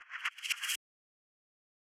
pbs - reverse shaker [ Perc ].wav